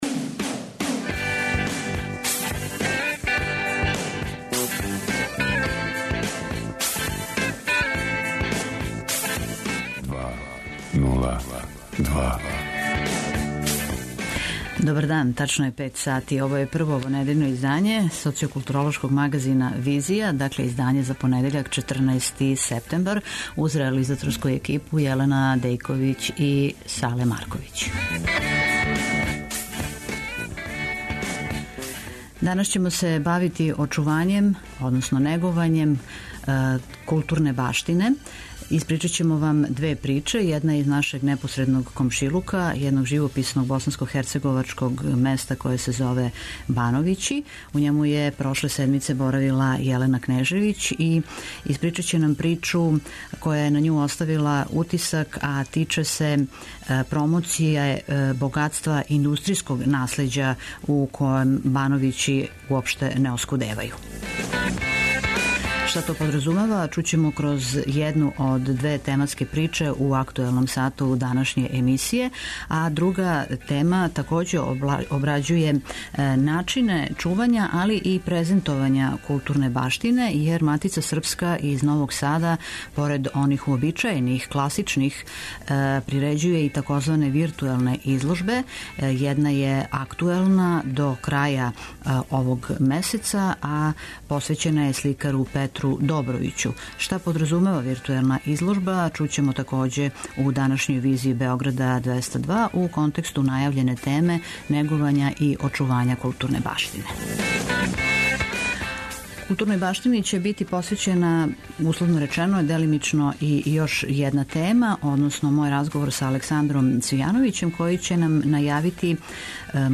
Утиске из живописне босанско-херцеговаче варошице Бановићи, за данашњу „Визију“ преноси репортер Двестадвојке. Слике тамошњих људи, догађаја и неговања традиције, последњих дана су били обогаћени многобројним гостима из региона, који су присуствовали свечаној премијери регионалне копродукције “Хиљадарка“, филма о чувеном југословенском рудару Алији Сиротановићу.